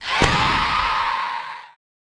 Faerie Dragon Yells
精灵龙叫声